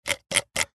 Звуки компьютерной мышки
Шум колесика мыши во время прокрутки